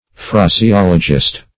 Phraseologist \Phra`se*ol"o*gist\, n. A collector or coiner of phrases.
phraseologist.mp3